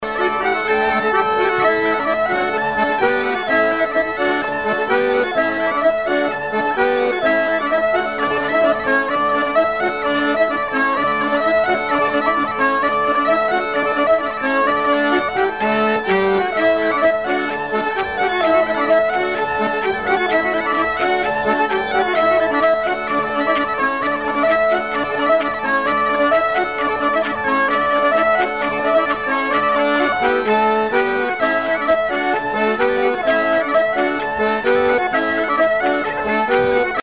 Divertissements d'adultes - Couplets à danser
danse : branle : courante, maraîchine
bal traditionnel à la Minoterie, à Luçon